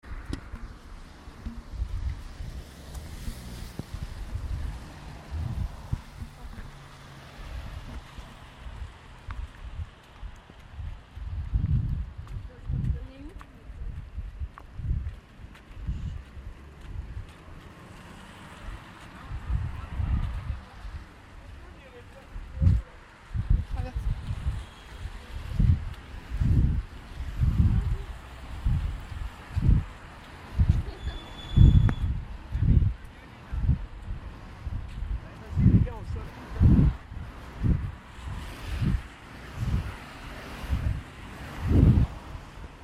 traffic routier